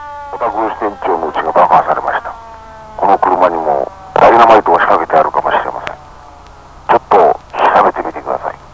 と次々に歴史上の人物達の語りかけです。